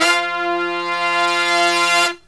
LONG HIT04-L.wav